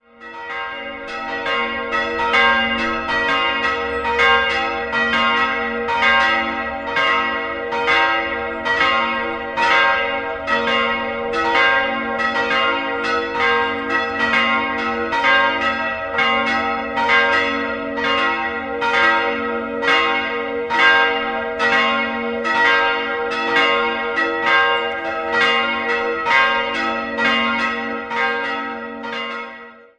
Rokoko. 3-stimmiges Gloria-Geläute: b'-c''-es'' Die beiden kleinen Glocken wurden 1948 von der Gießerei Hamm in Regensburg gegossen, die große aus dem Hause Hofweber kam 1959 dazu.